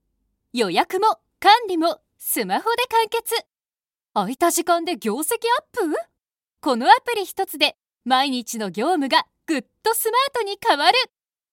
声の達人女性ナレーター
落ち着いた／穏やか
ボイスサンプル2（明るい・サービス紹介）[↓DOWNLOAD]